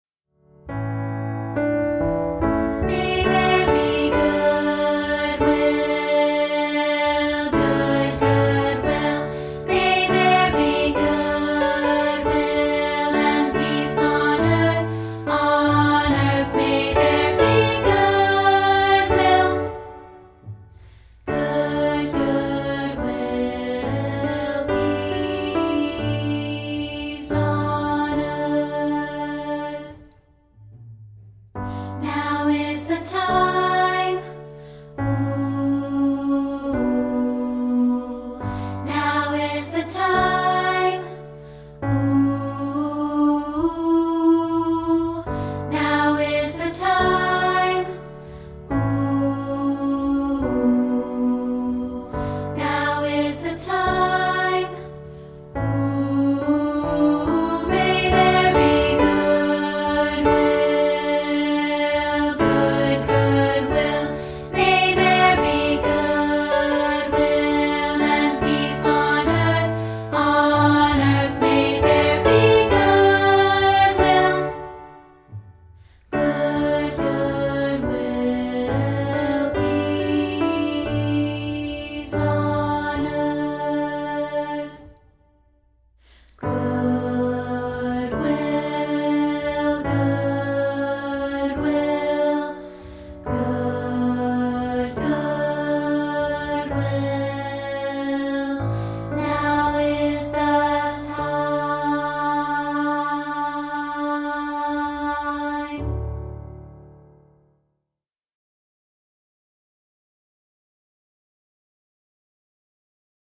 We are offering a rehearsal track of part 2, isolated